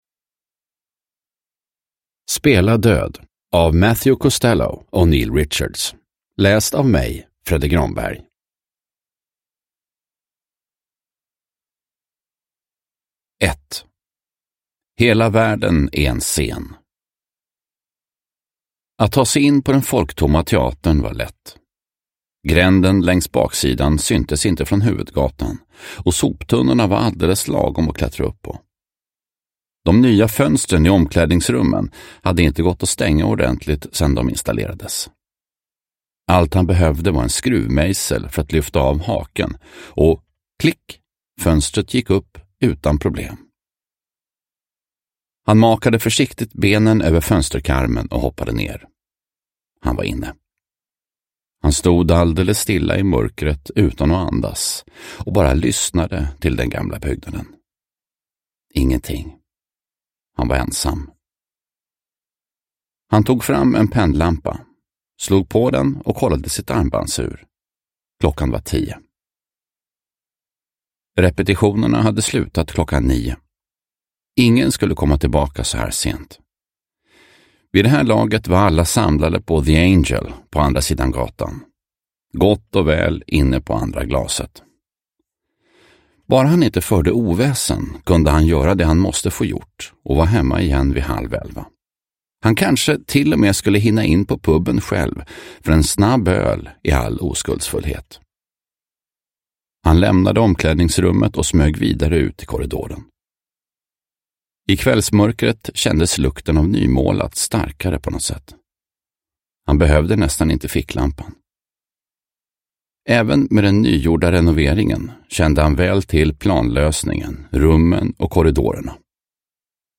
Spela död – Ljudbok – Laddas ner